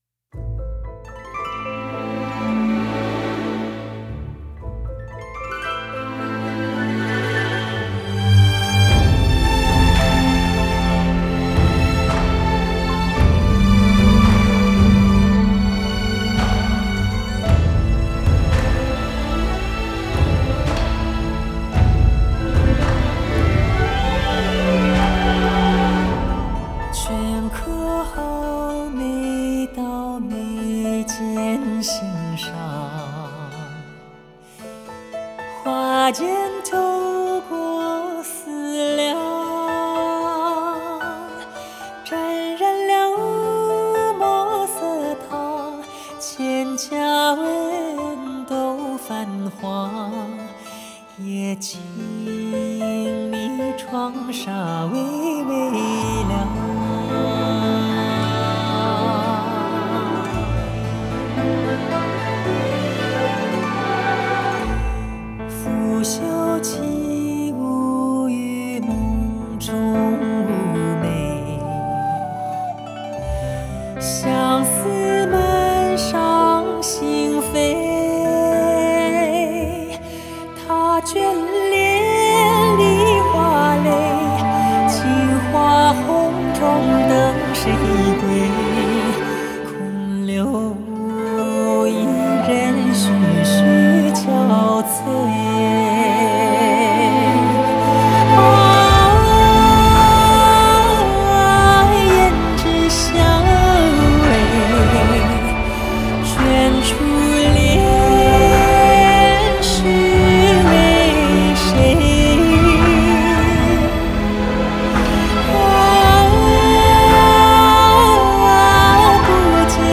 Ps：在线试听为压缩音质节选，体验无损音质请下载完整版
Recording Studio 录音室：上海广播大厦一号录音棚